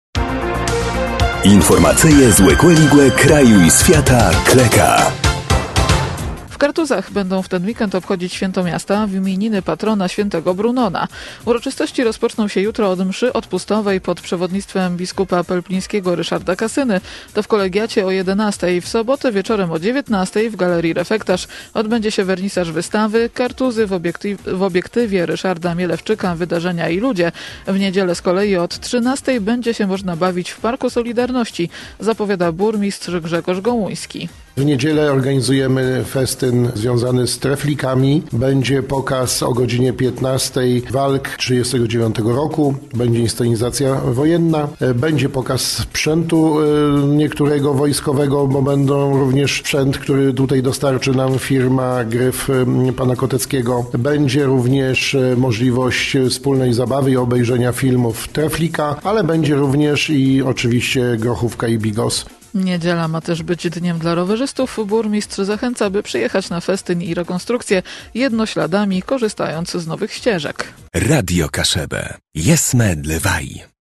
– W niedzielę z kolei od 13:00 będzie się można bawić w Parku Solidarności – zapowiada burmistrz Grzegorz Gołuński.